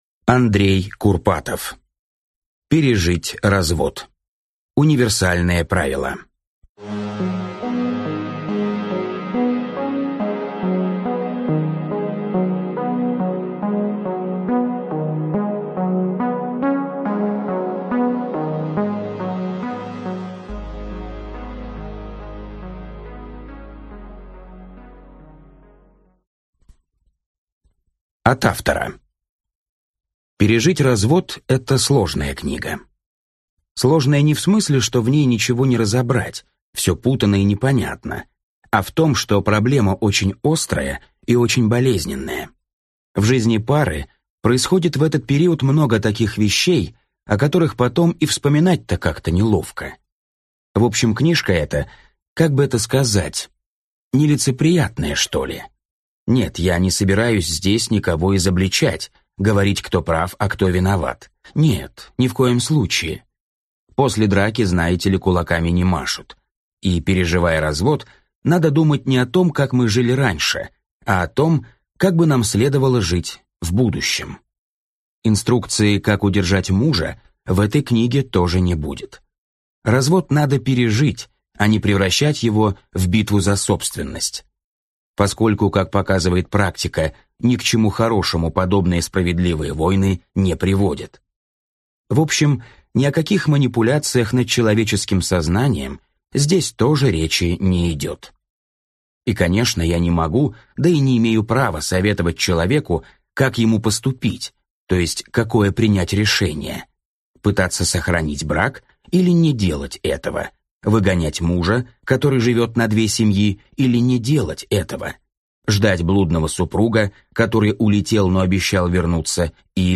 Аудиокнига Пережить развод. Универсальные правила | Библиотека аудиокниг